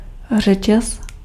Ääntäminen
France (Paris): IPA: [yn ʃɛn]